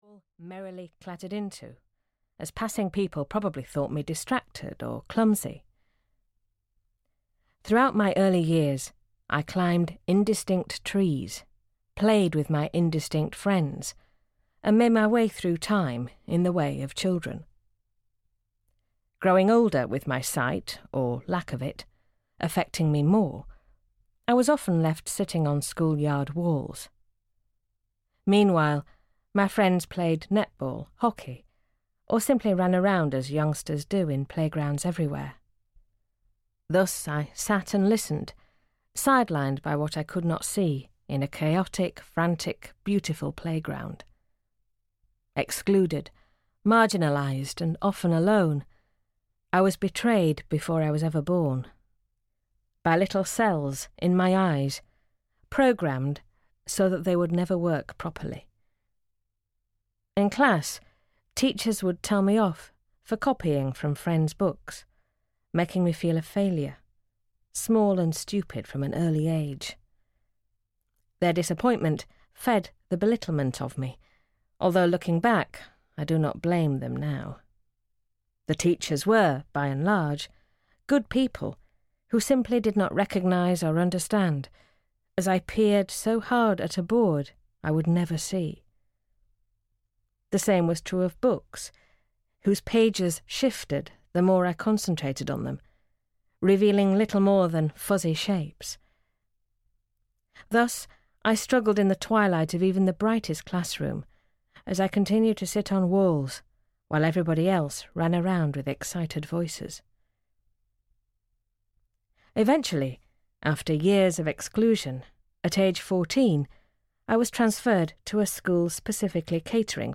Touching the World (EN) audiokniha
Ukázka z knihy